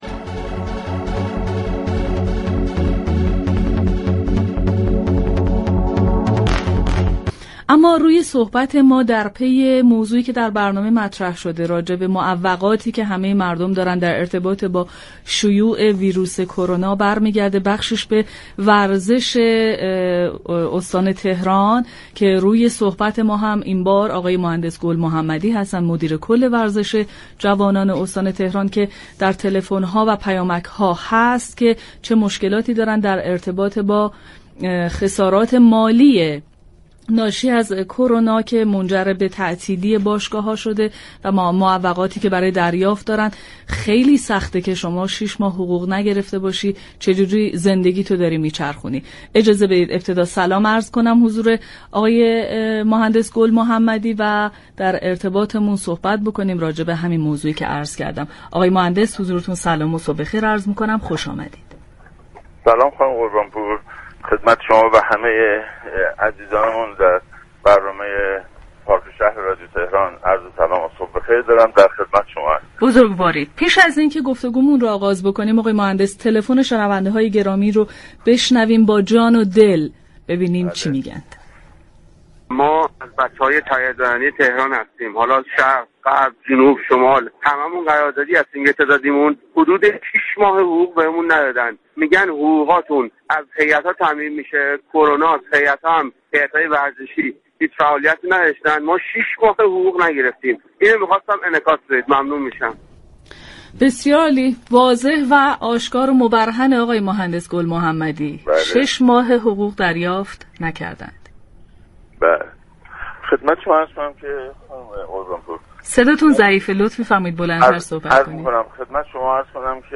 مهندس رضا گل محمدی، مدیر كل ورزش جوانان استان تهران در گفتگو با پاركشهر گفت: امكان ندارد كسی در اداره كل ورزش تهران و هیات های وابسته به آن شش ماه حقوق نگرفته باشد.